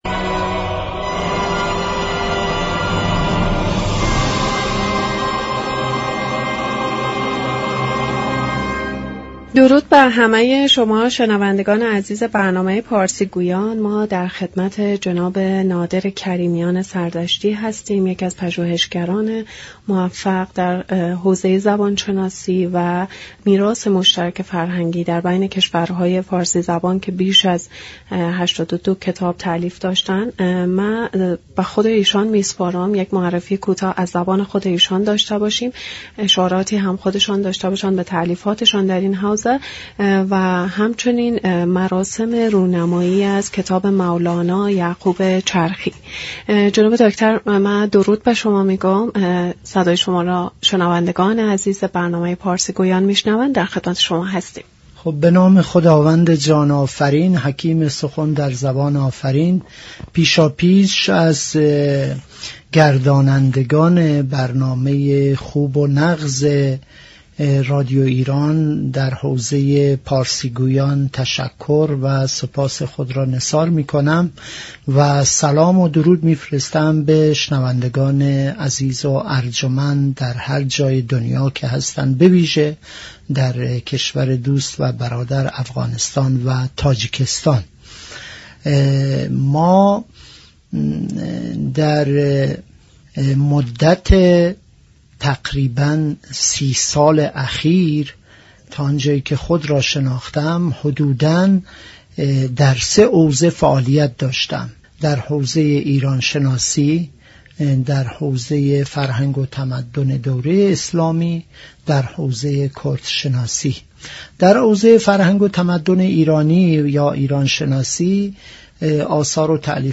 گفت و گو با رادیو ایران